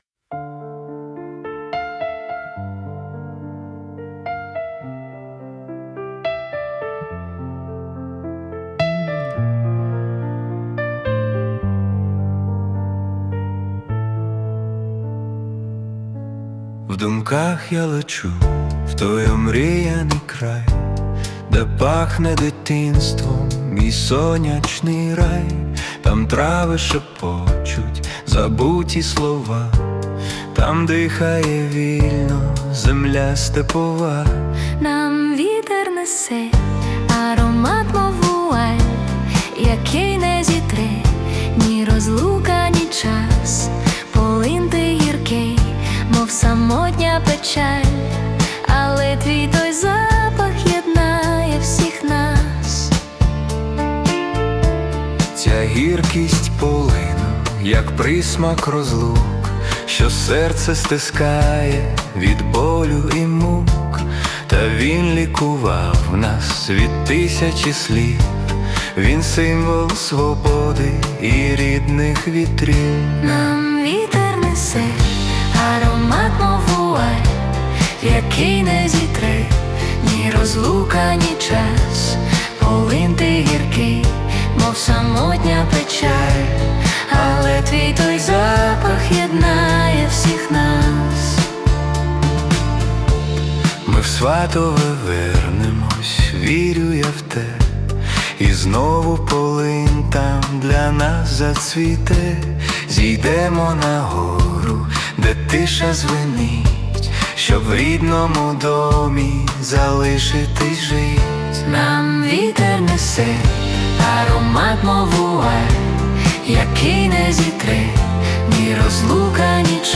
🎵 Жанр: Folk-Pop / Nostalgic